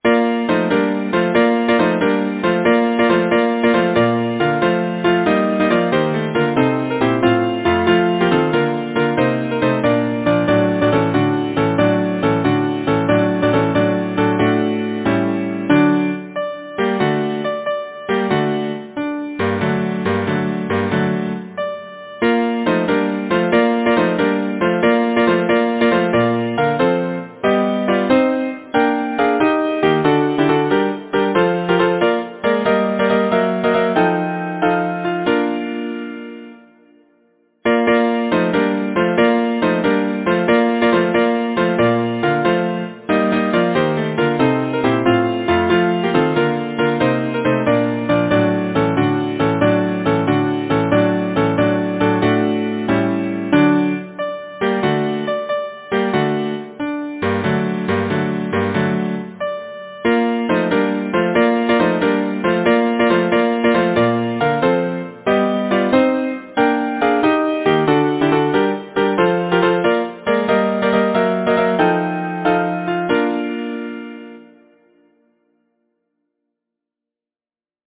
Title: The Spring Composer: Henry Lahee Lyricist: Samuel William Partridge Number of voices: 4vv Voicing: SATB Genre: Secular, Partsong
Language: English Instruments: A cappella